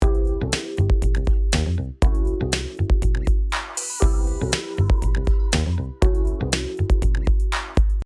Here I have simply put on Logic’s Space Designer IR reverb with 100% dry and about 5% wet and set to a 1/4 note decay (500 ms in this case-good idea is to set your reverb to musical settings and so it decays before the next hit).
The track after bus reverb; thicker and deeper.
Reverb After.mp3